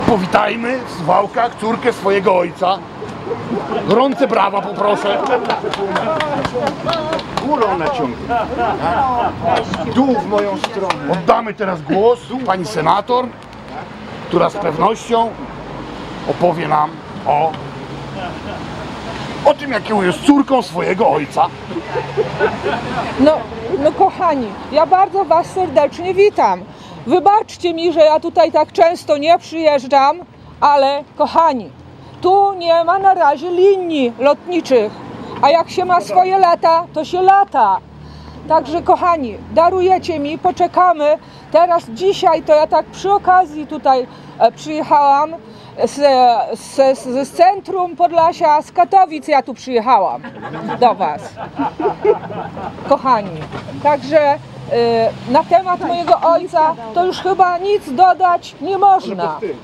Scenkę przed biurem senatorskim Anny Marii Anders i poselskim Jarosława Zielińskiego w Suwałkach odegrała w czwartek (17.05) grupa kilkunastu osób.
Mężczyzna z maską z wizerunkiem Jarosława Zielińskiego witał senator w Suwałkach.
Wszystko w dosyć prześmiewczej formie.